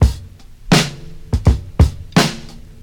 • 84 Bpm Breakbeat C Key.wav
Free drum beat - kick tuned to the C note. Loudest frequency: 1154Hz
84-bpm-breakbeat-c-key-IJ9.wav